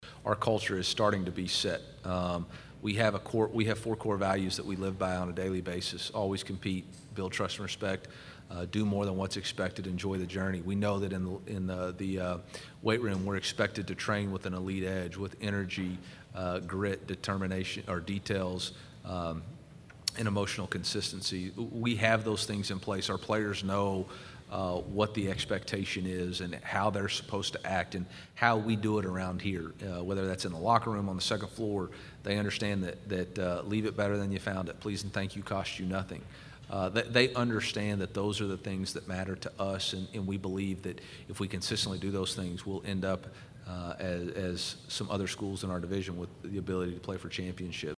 Missouri head coach Eliah Drinkwitz speaks during an NCAA college football news conference at the Southeastern Conference Media Days, Monday, July 18, 2022, in Atlanta.